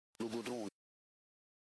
goudron_prononciation.mp3